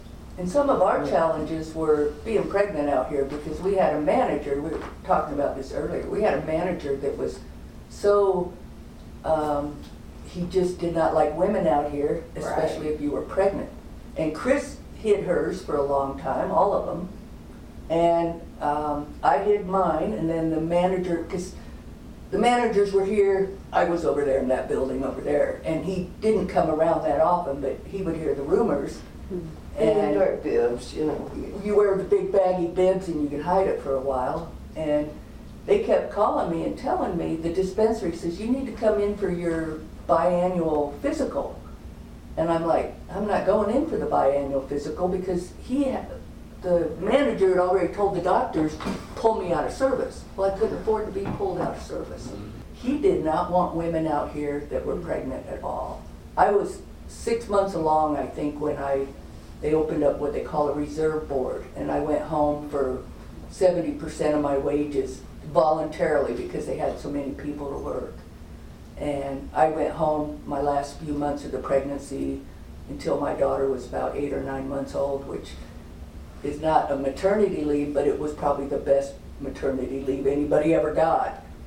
“Life Between the Rails” oral history project, Coll.